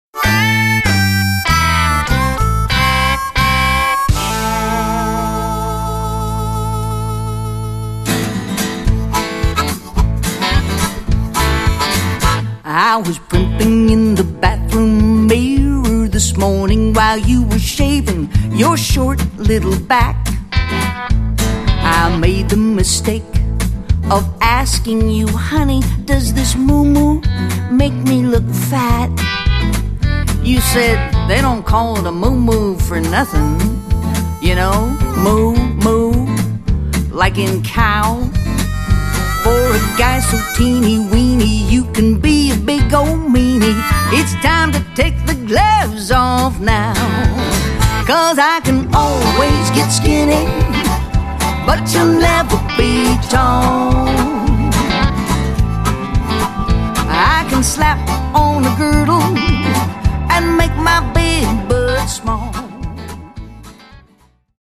comedy music